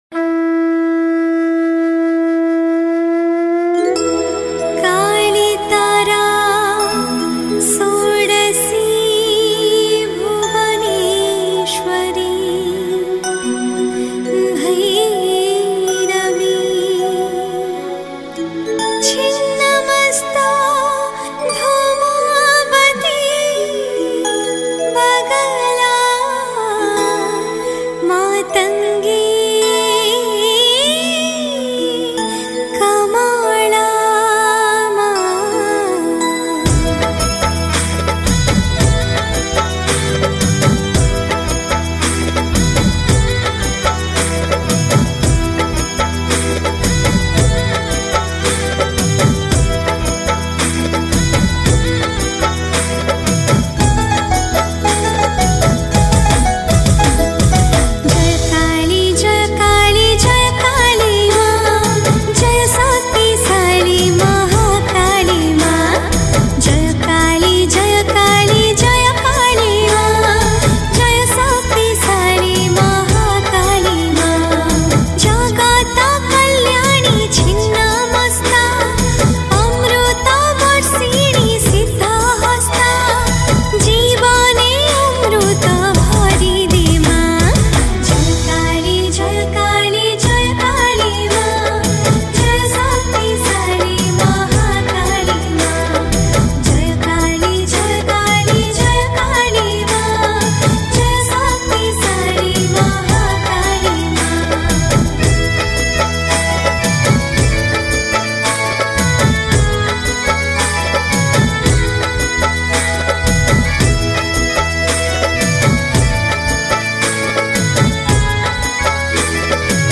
Music - Traditional